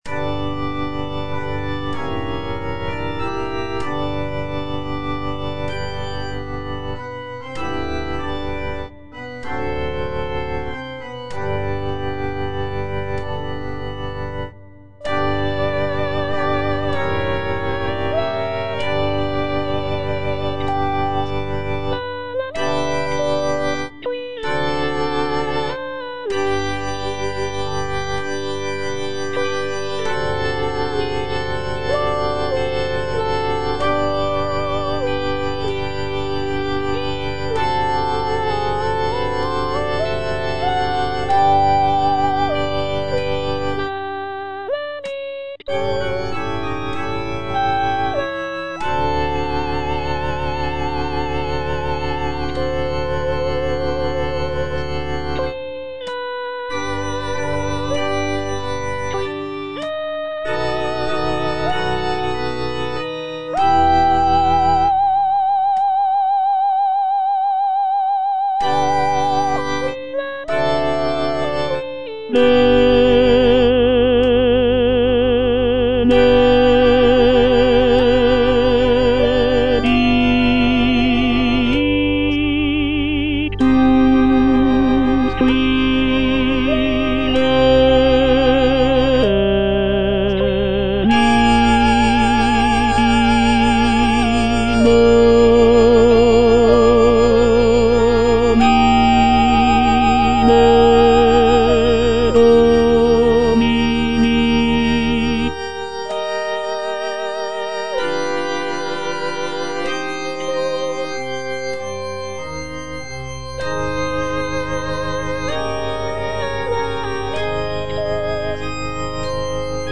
C.M. VON WEBER - MISSA SANCTA NO.1 Benedictus - Tenor (Voice with metronome) Ads stop: auto-stop
"Missa sancta no. 1" by Carl Maria von Weber is a sacred choral work composed in 1818.
The work features a grand and powerful sound, with rich harmonies and expressive melodies.